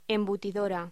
Locución: Embutidora